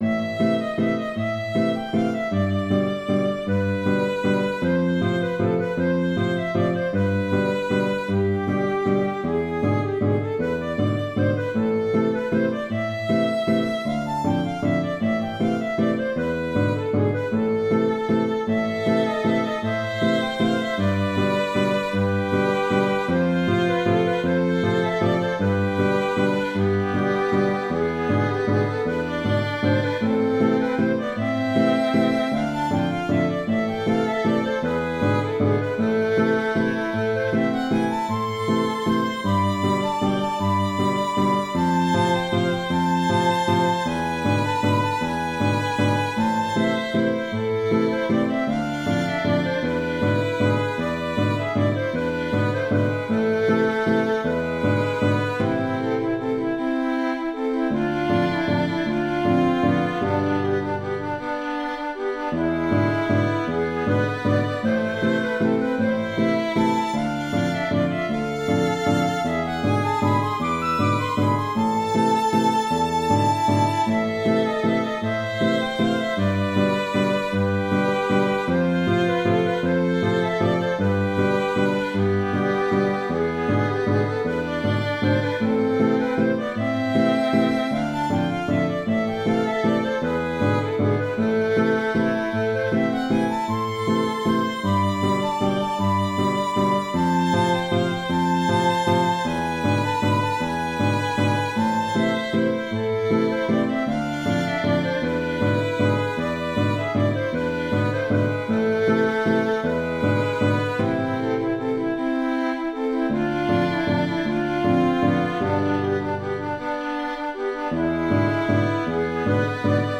Automne 20 Valse L’automne 2020, c’est le deuxième confinement de l’année pour cause de pandémie. La forêt continue de vivre en changeant de couleur.
La ligne mélodique convient pour un accordéon diatonique à trois rangées.